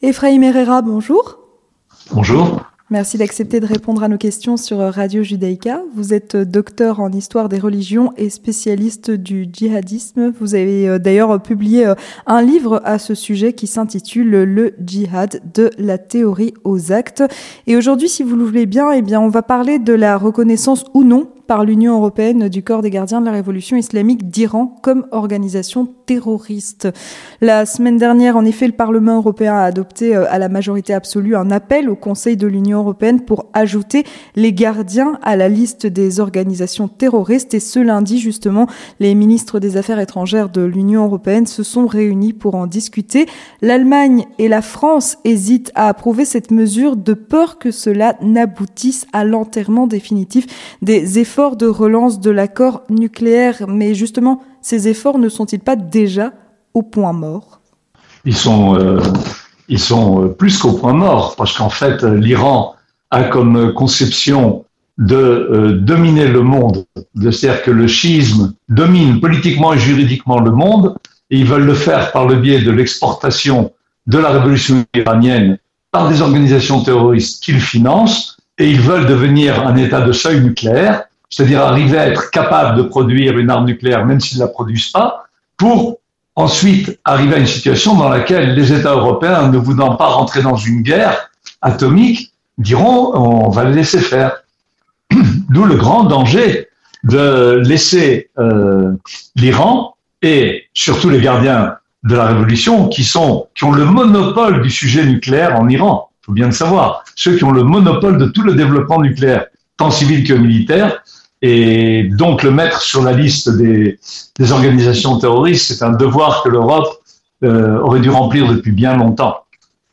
L’ENTRETIEN DU 18H